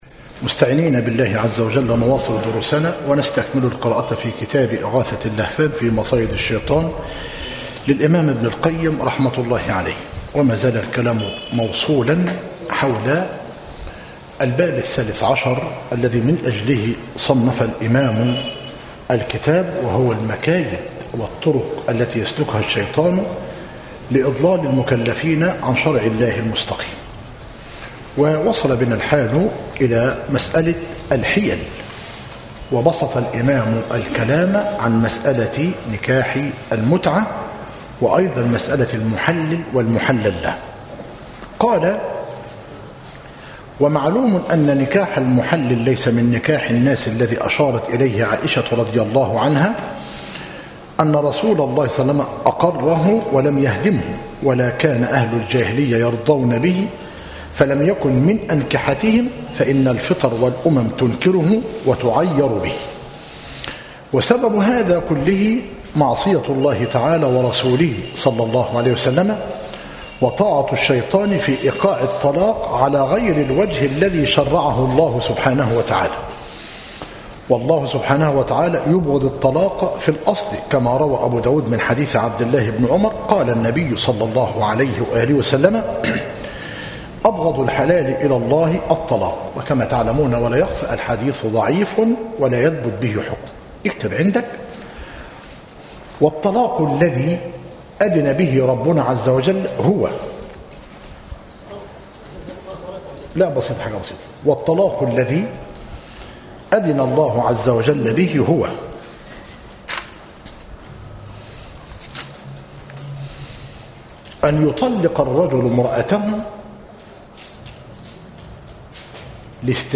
إغاثة اللهفان - مسجد عباد الرحمن - المنايل - كفر حمزة - قليوبية - المحاضرة الثالثة والخمسون - بتاريخ 30- جماد آخر- 1436هـ الموافق 19- إبريل - 2015 م